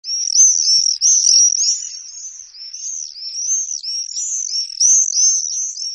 En cliquant ici vous entendrez le chant du Martinet.
Le Martinet noir